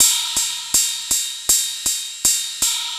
Ride 02.wav